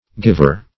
Giver \Giv"er\, n.